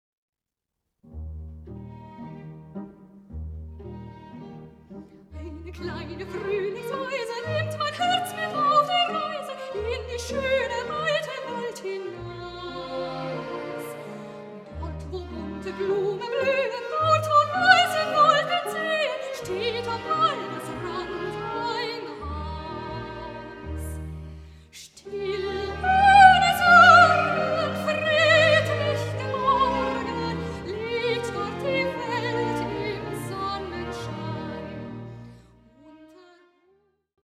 Sopran